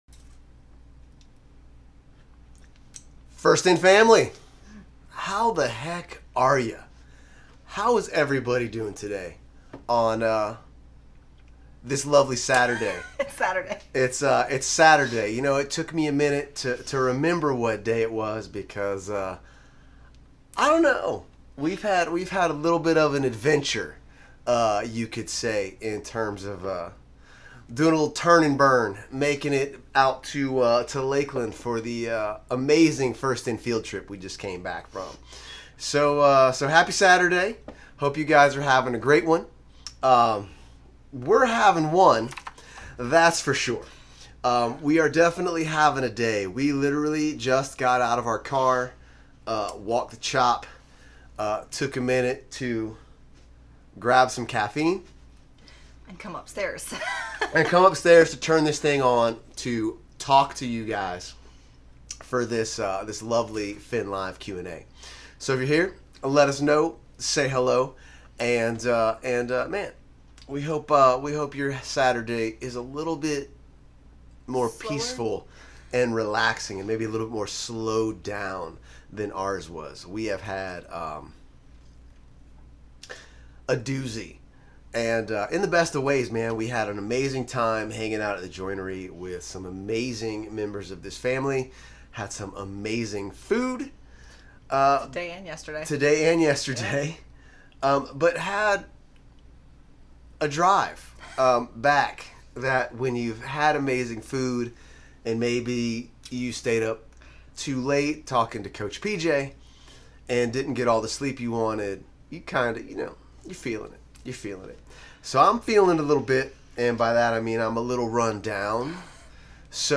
FIN LIVE Q+A: 7/24/21